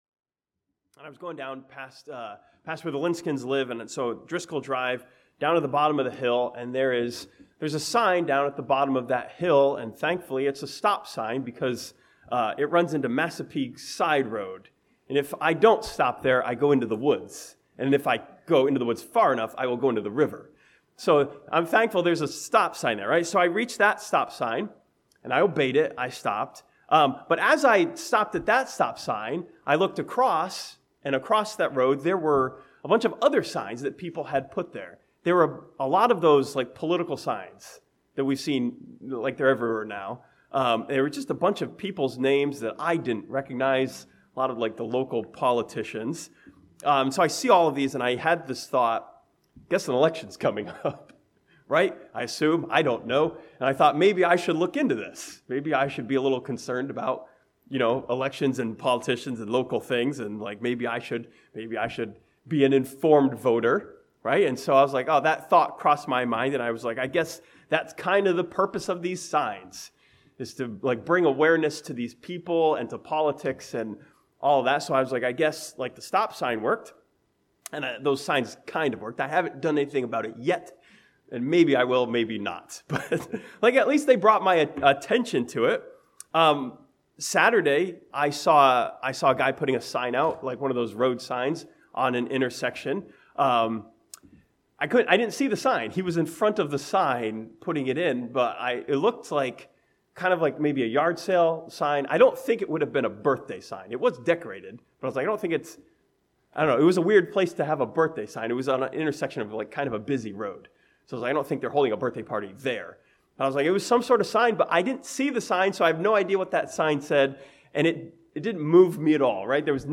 This sermon from Isaiah 8 studies the sign given by God to King Ahaz that challenges him to believe and be established.